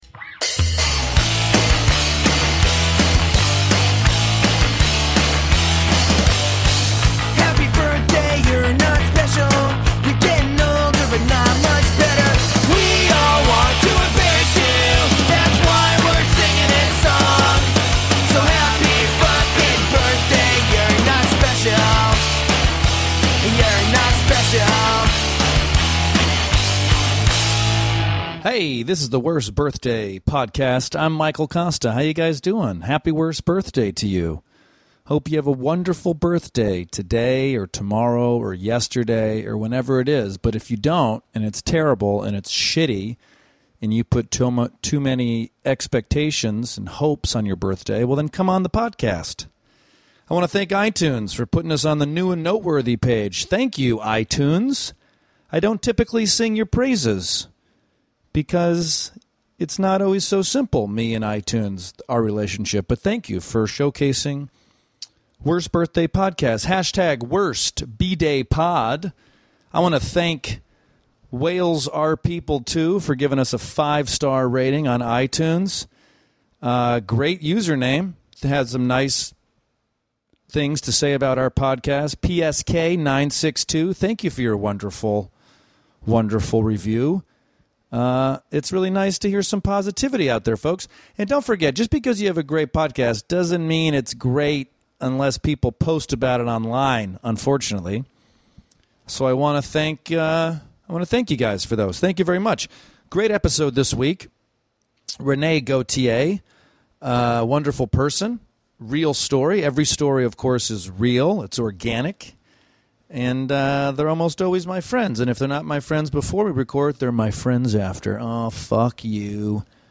Kevin Eastman co-created the Teenage Mutant Ninja Turtles, and for that, we are in eternally grateful. In this special LIVE conversation from the San Diego Comic Art Gallery, we hear stories of how the Turtles came to be, working with Jim Henson, the unexpected success of the ...